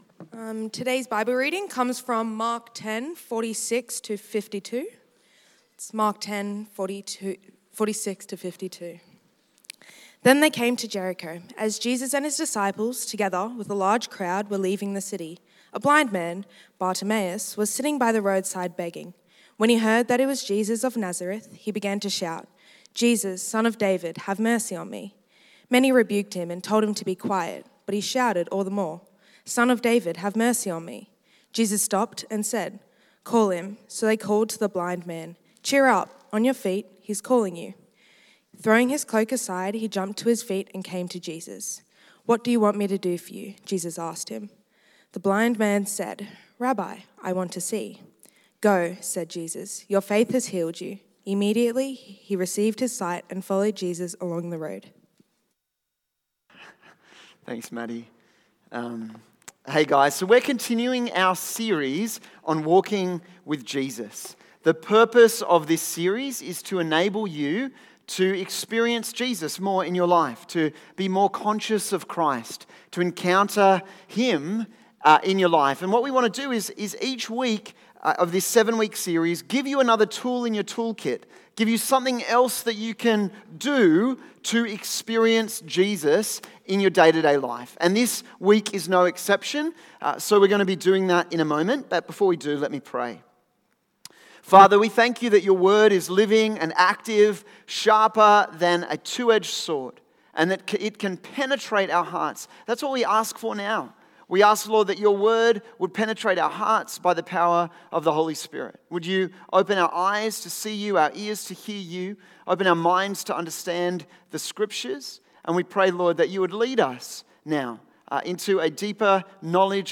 Service Type: 6PM